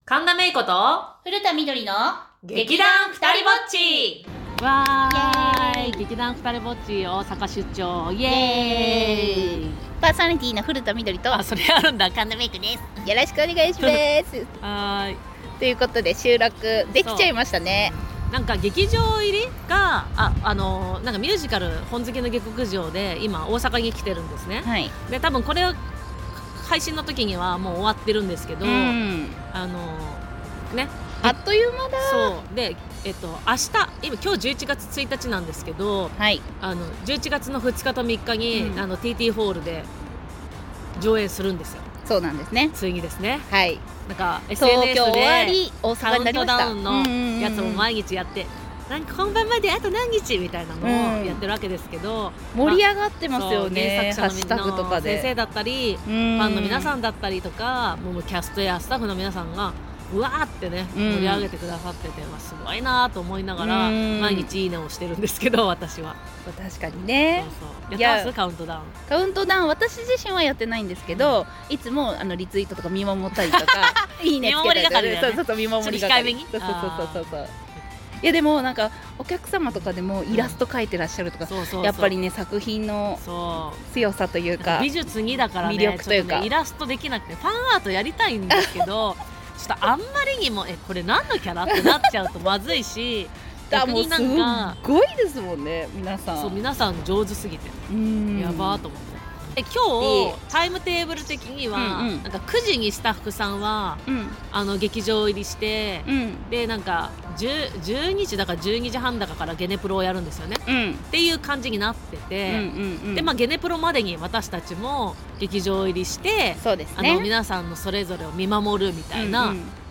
大阪出張、朝活での一コマをお届けします！
せっかく二人揃ったならと、朝活で収録してきました！